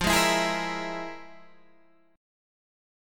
FmM7bb5 chord